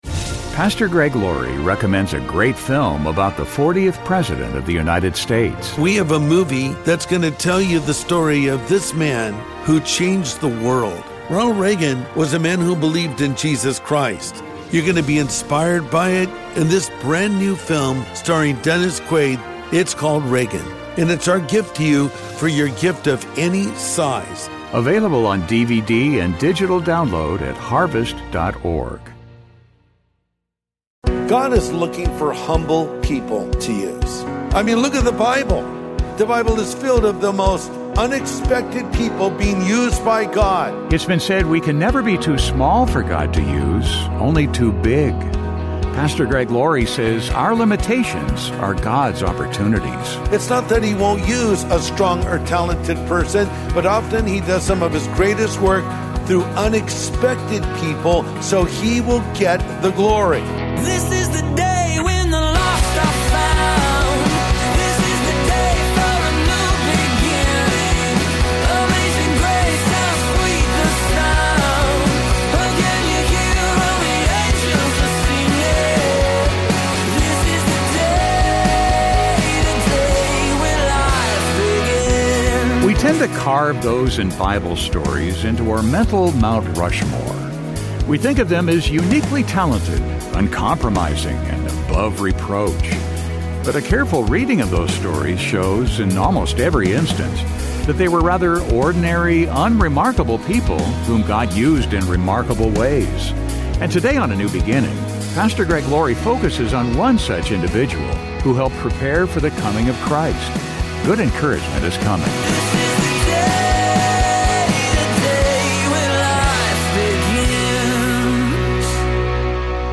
But a careful reading of those stories shows, in almost every instance, that they were rather ordinary, unremarkable people whom God used in remarkable ways. And today on A NEW BEGINNING, Pastor Greg Laurie focuses on one such individual who helped prepare for the coming of Christ.